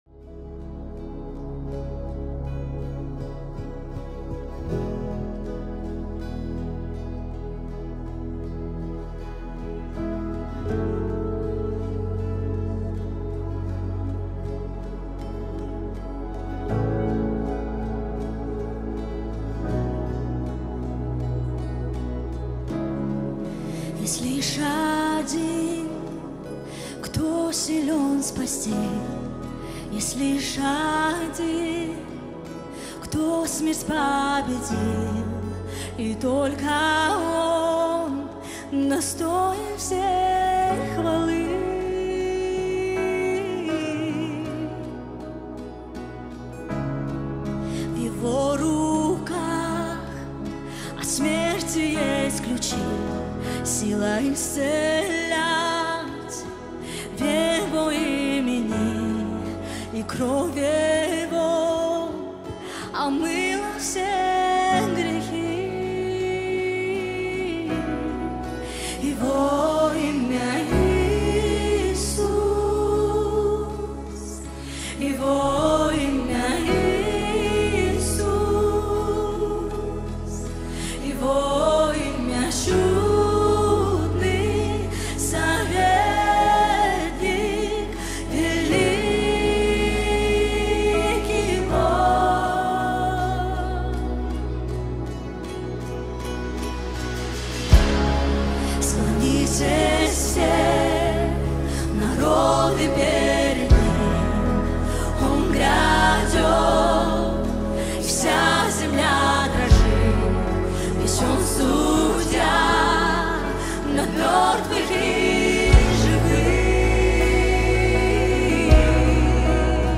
22010 просмотров 7756 прослушиваний 1016 скачиваний BPM: 80